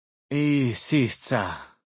Category:Dead Horses pidgin audio samples Du kannst diese Datei nicht überschreiben.